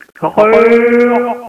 ist der An-, Erkennungs- oder Schlachtruf der Elche.